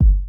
ShaMoneyXL_Kick_02.wav